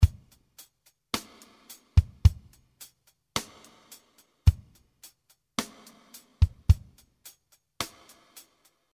54 bpm real drum loops.
This beat use big x-stick and snare with long reverb. This beat was recorded with professional drummer and the rhythm is very accurate.
Use this beat for ballad and romantic songs.